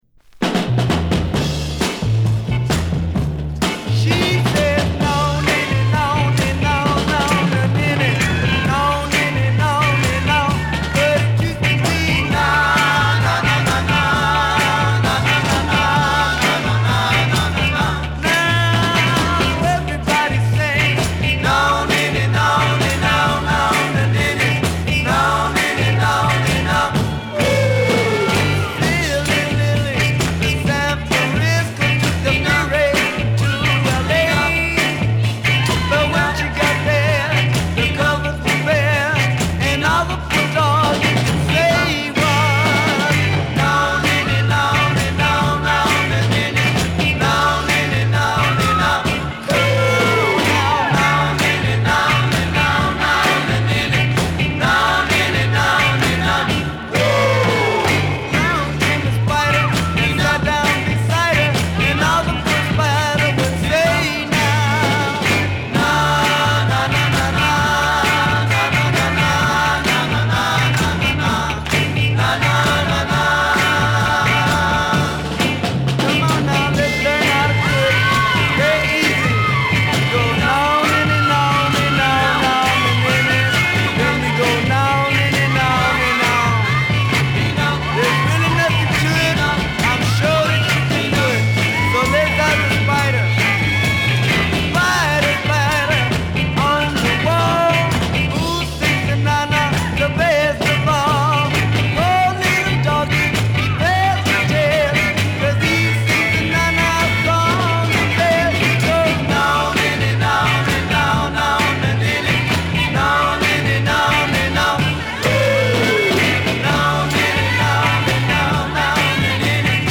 熱血チカーノ・ロックンロール〜フラット・ダンサー。
たどたどしさは相変わらずだが、演奏はほとばしるほど熱い。